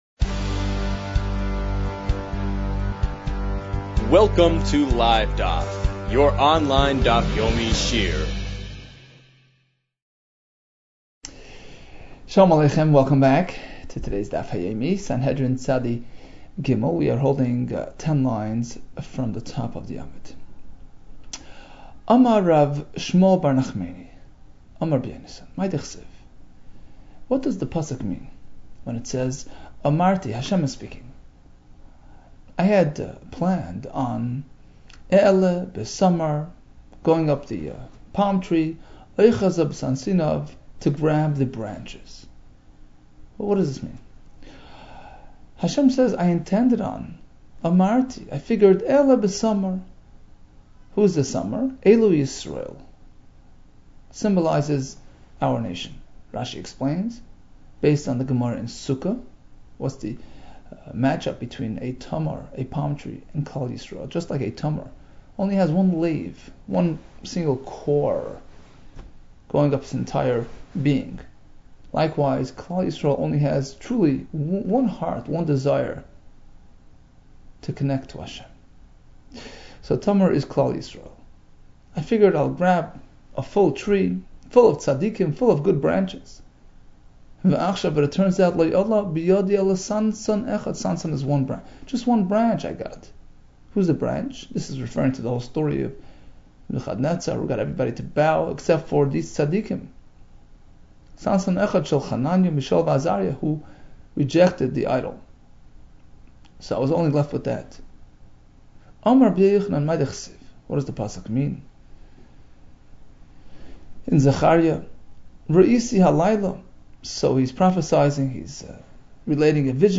Sanhedrin 92 - סנהדרין צב | Daf Yomi Online Shiur | Livedaf